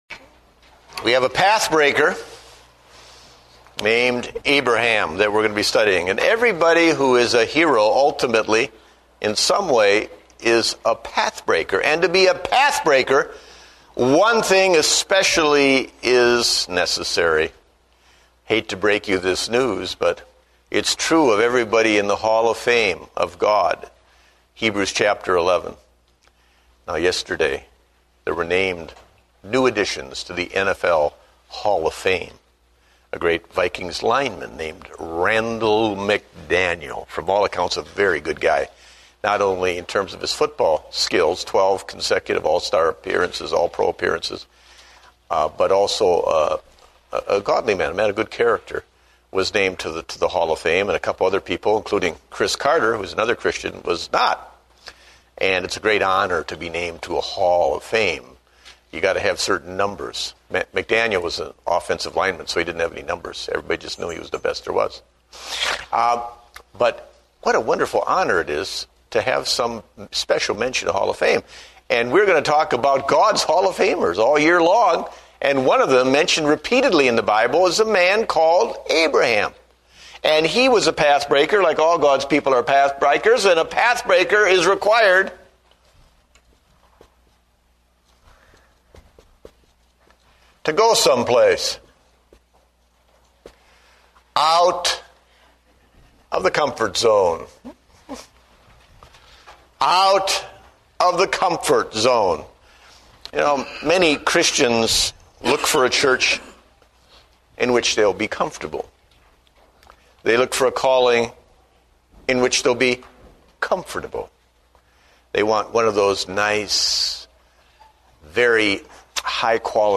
Date: February 1, 2009 (Adult Sunday School)